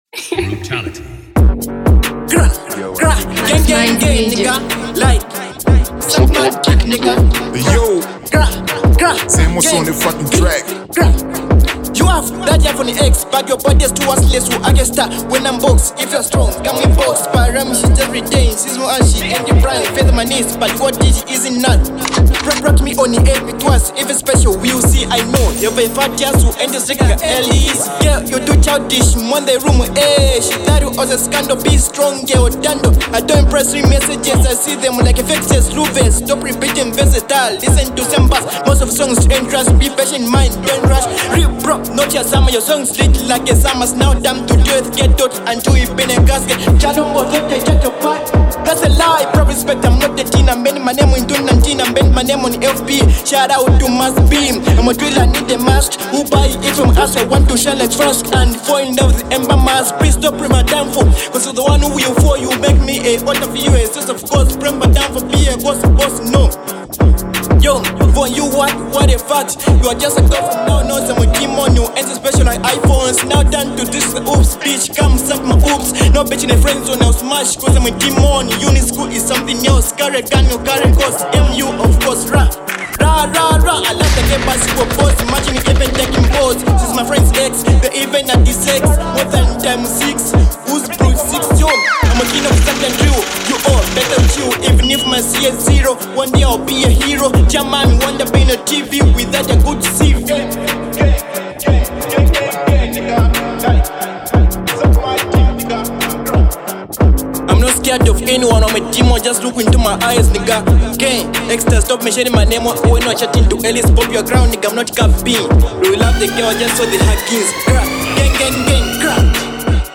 a Zambian drill rapper
hard-hitting track